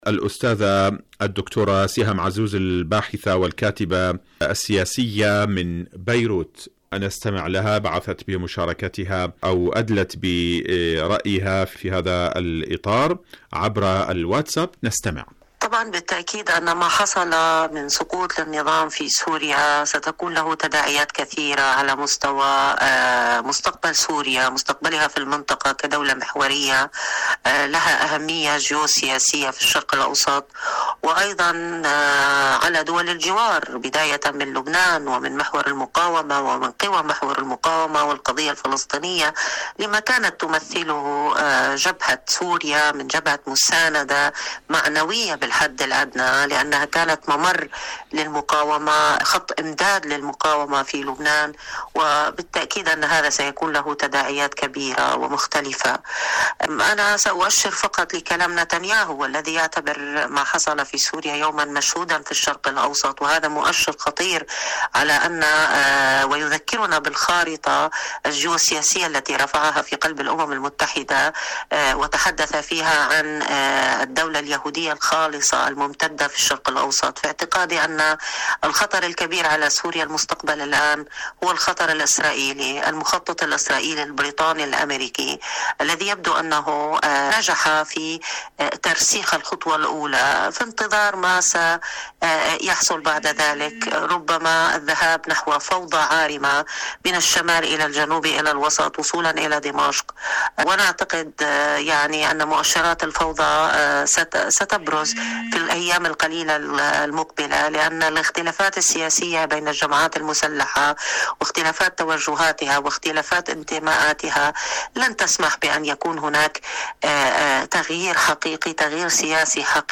سقوط نظام الأسد في سوريا.. مقابلة